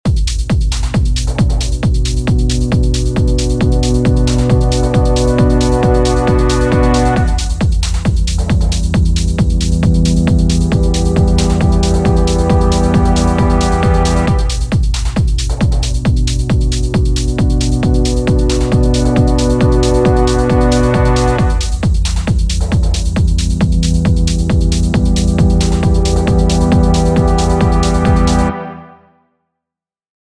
Right click the Filter Cutoff and select Show Automation to draw in an envelope for the cutoff. Have it start from 50 Hz and increase slowly to reach a high value at the end of every four bars.